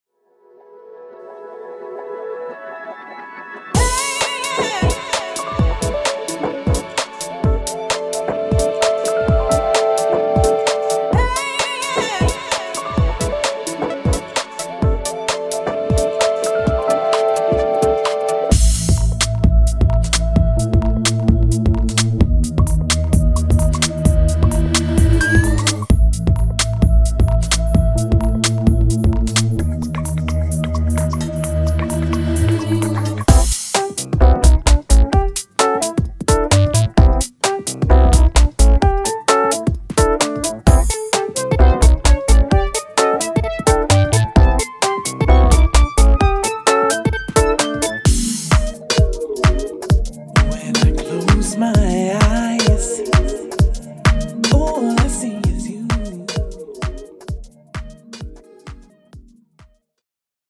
Uk Garage